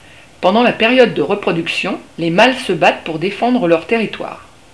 Le cri du merle noir. Au printemps, le mâle chante d'un toit pour attirer les femelles.